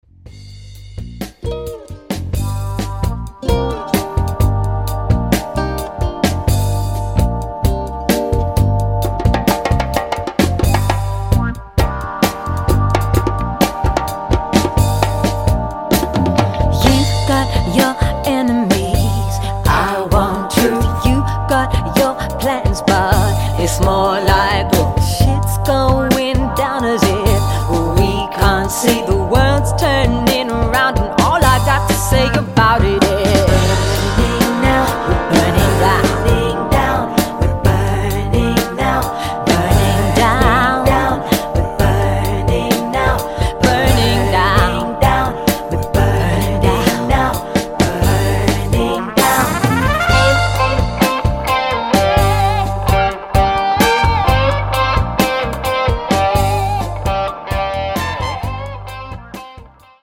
Excellent modern Acid jazz vibes